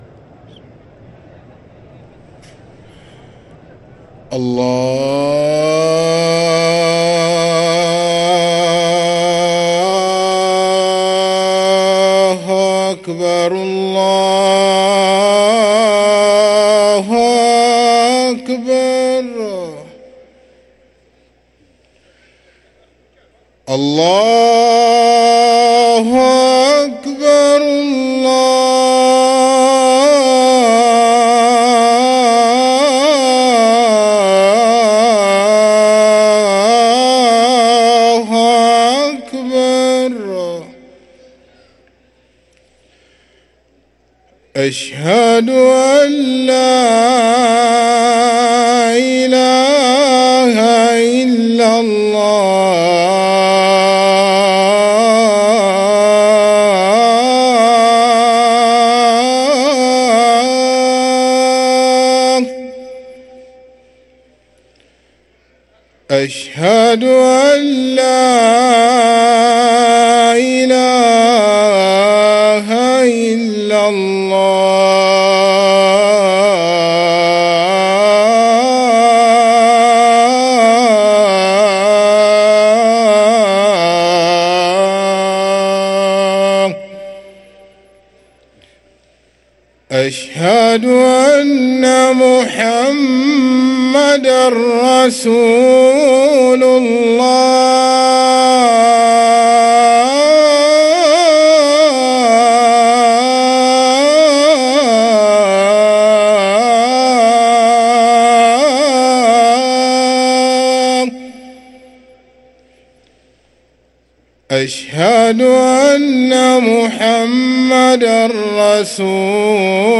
أذان الفجر للمؤذن سعيد فلاته الثلاثاء 5 شوال 1444هـ > ١٤٤٤ 🕋 > ركن الأذان 🕋 > المزيد - تلاوات الحرمين